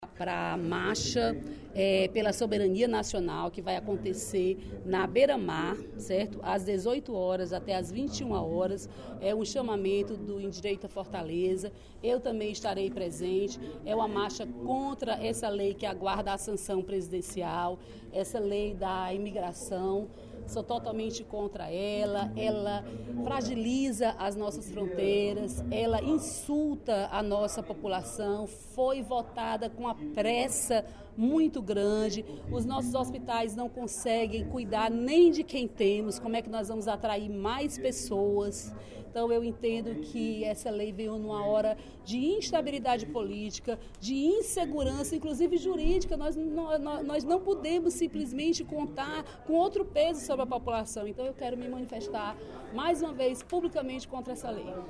A deputada Dra. Silvana (PMDB) comunicou, nesta terça-feira (16/05), durante o primeiro expediente da sessão plenária, a realização da Marcha pela Lei de Soberania Nacional contra a Nova Lei da Migração.